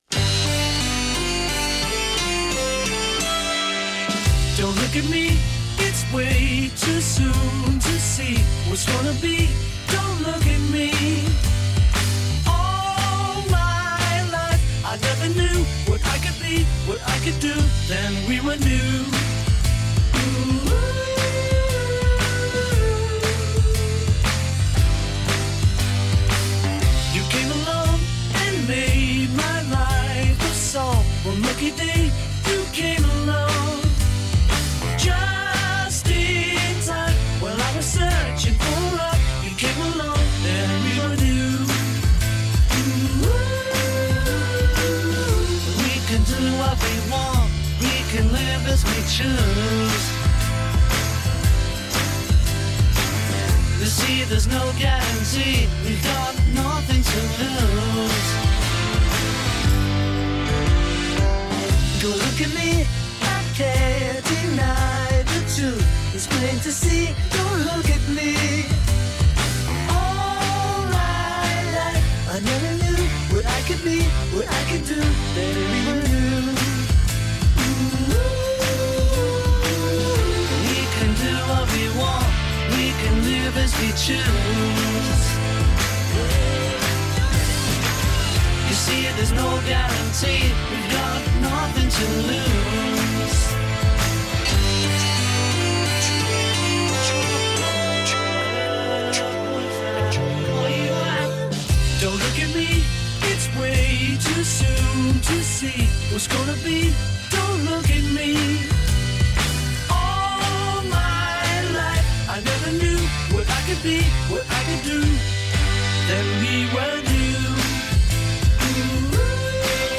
AI to the rescue